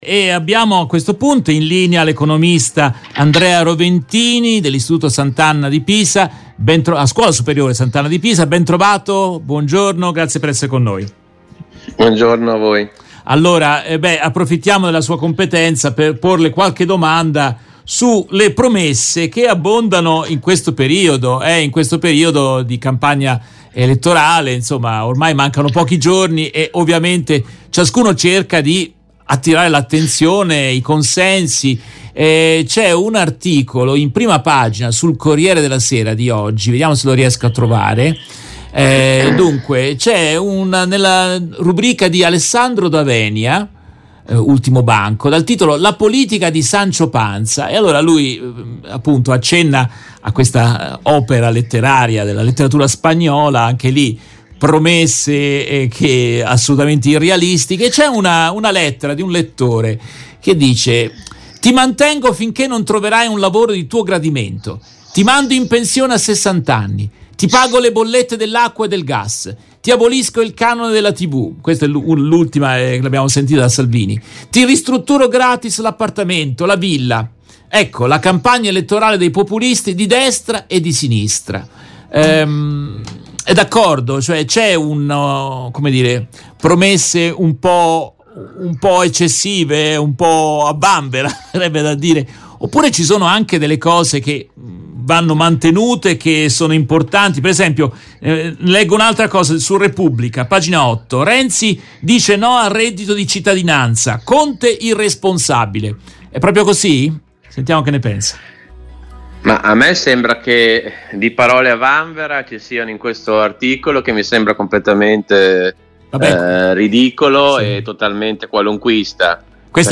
In questa intervista tratta dalla diretta RVS del 19 settembre 2022